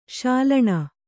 ♪ śalaṇa